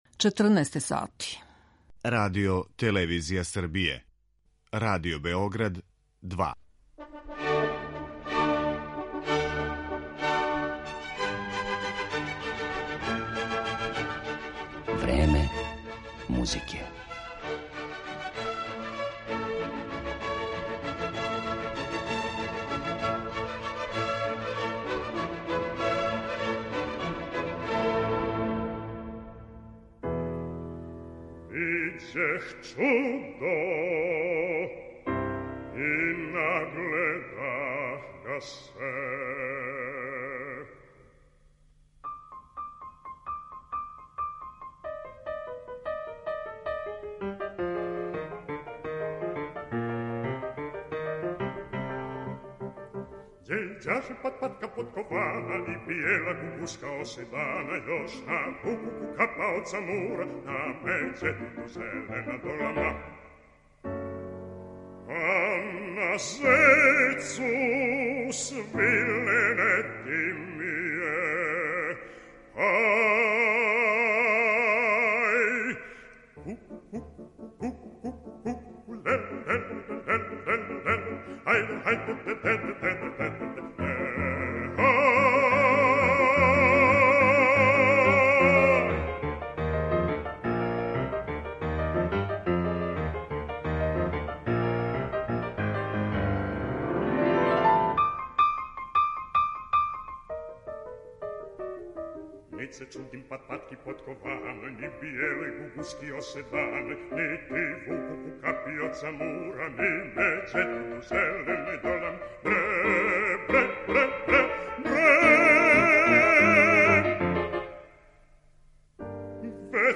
испуниће духовите музичке странице наших композитора.